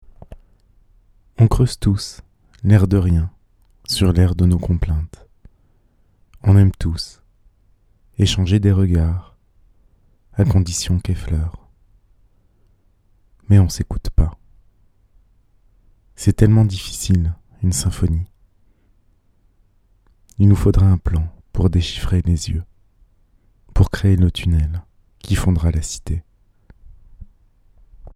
dans Poésie sonore